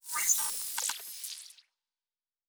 Sci-Fi Sounds / Electric / Device 9 Stop.wav
Device 9 Stop.wav